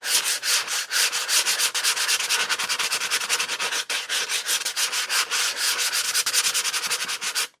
in_sandpaper_04_hpx
Wood being sanded by hand. Tools, Hand Wood, Sanding Carpentry, Build